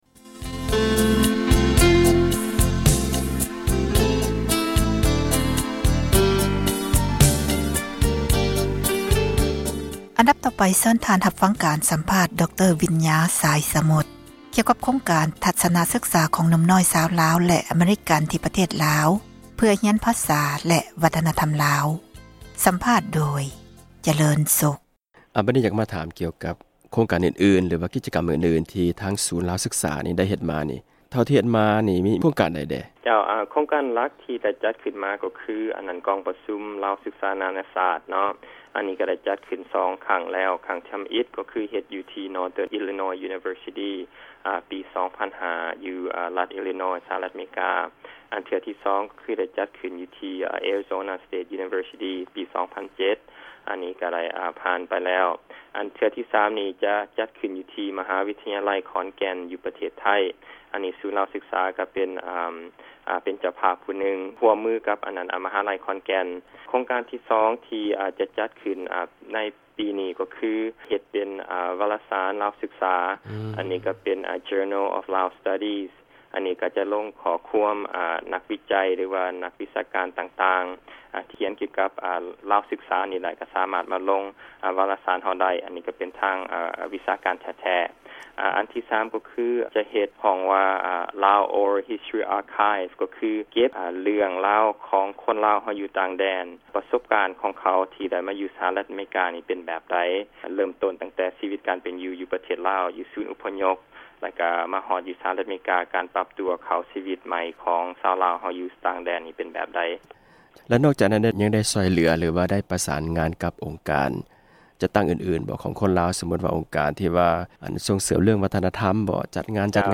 ການສັມພາດ ຕອນທີ 3 ນີ້